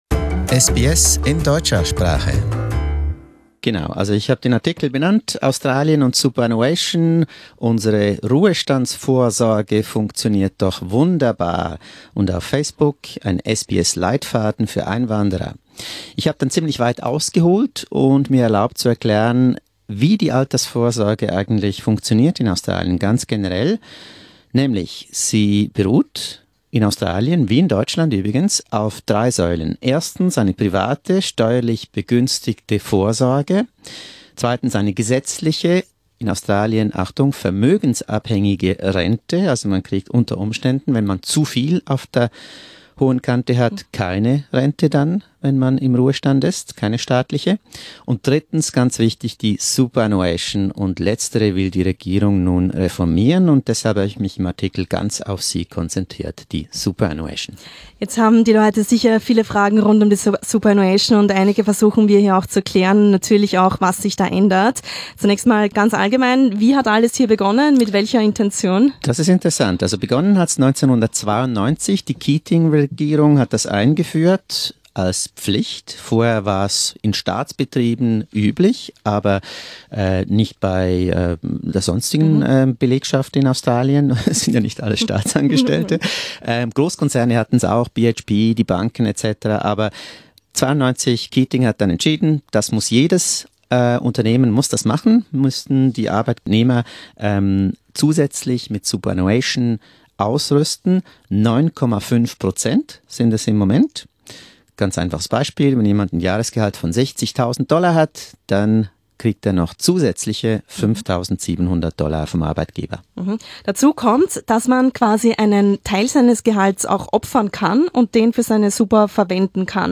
SBS studio interview: Wie funktioniert die australische Altersvorsorge?